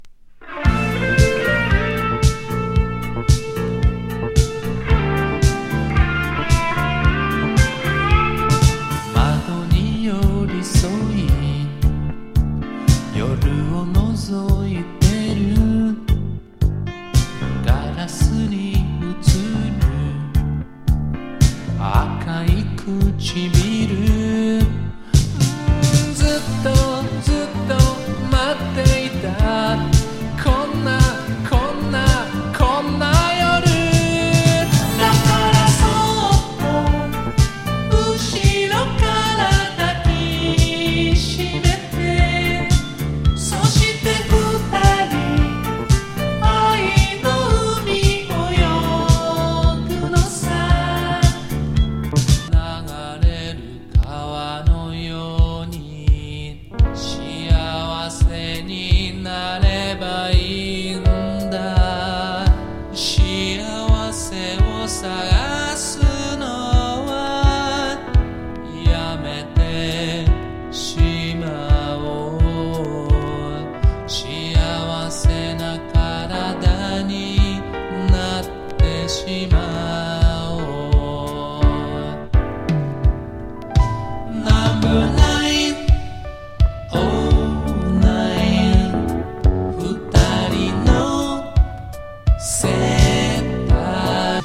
レトロフューチャー妄想SSWグルーブ